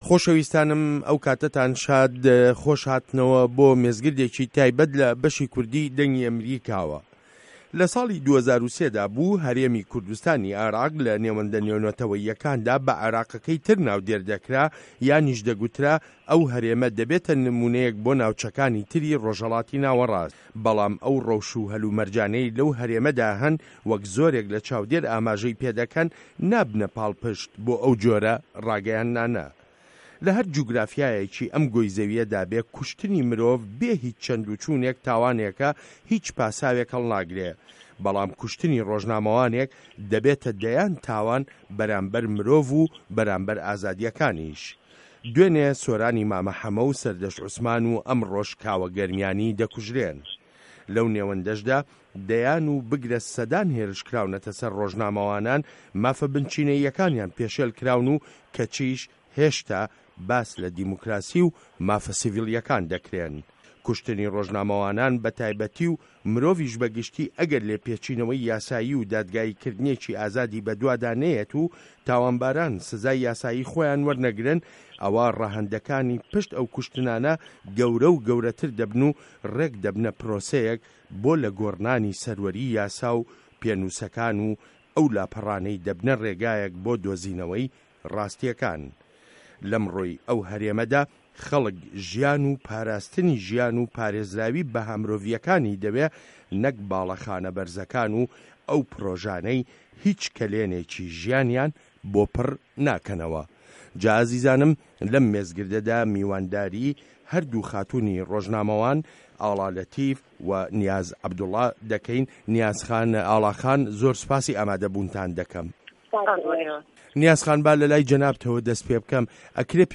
مێزگرد: کوشتنی ڕۆژنامه‌وانان له‌ ژینگه‌ی دیموکراسیدا